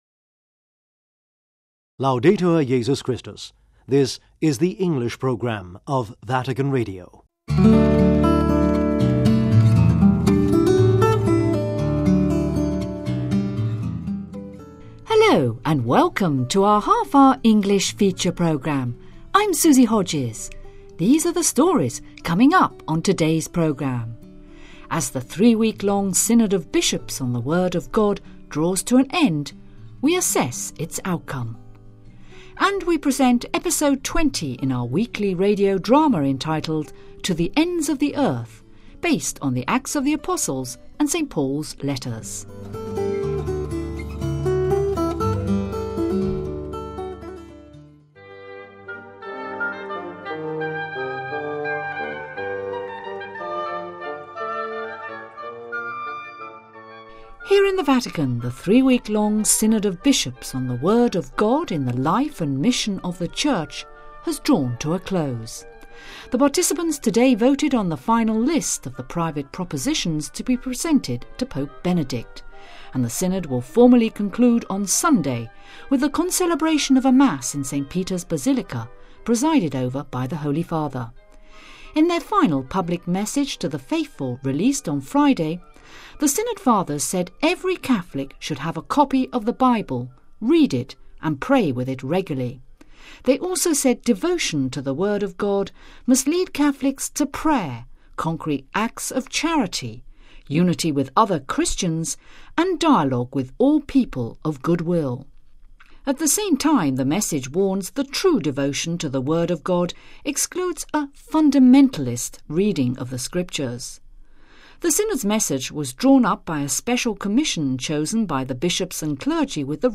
SYNOD CONCLUSIONS : As the three week long Synod of Bishops on the Word of God comes to an end we assess its outcome...... TO THE ENDS OF THE EARTH : We present episode 20 in our weekly radio drama based on the Acts of the Apostles and St. Paul's letters........